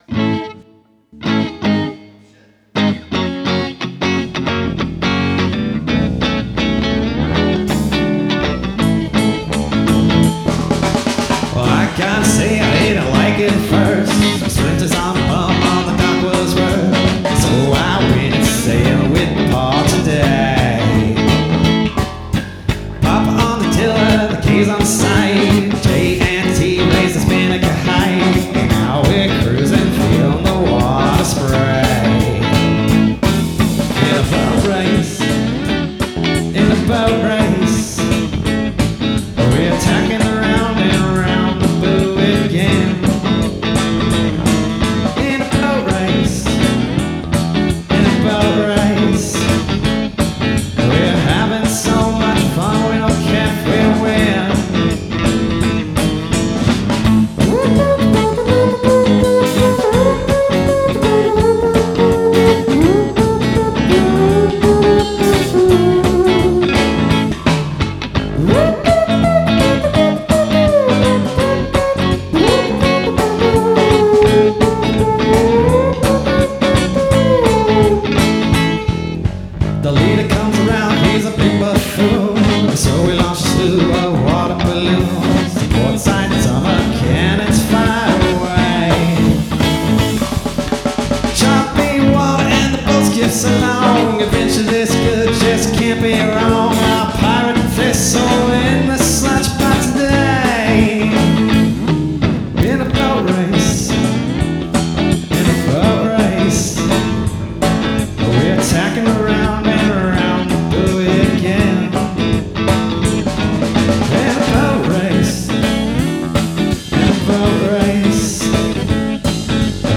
Cold Cut || Demo ||